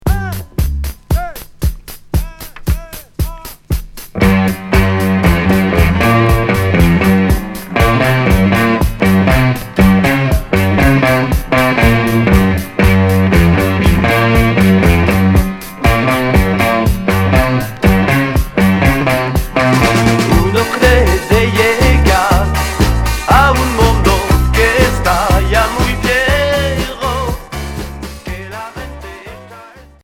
Pop progressif